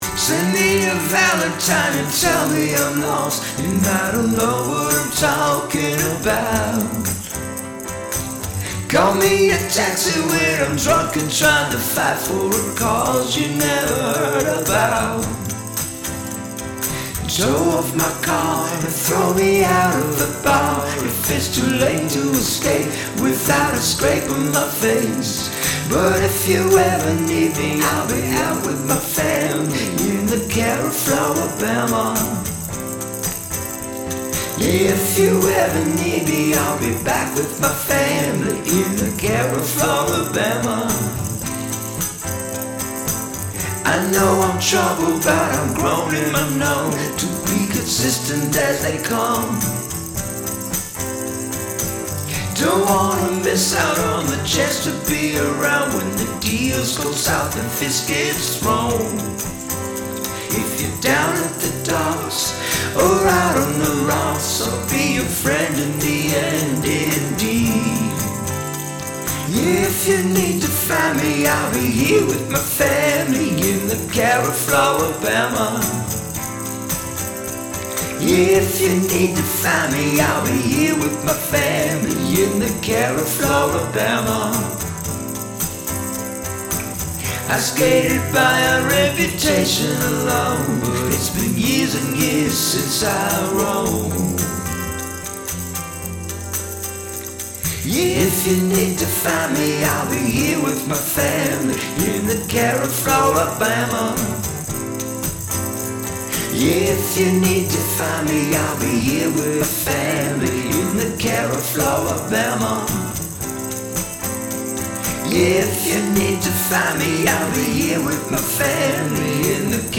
Love the harmonies on this.
Tremendous chill.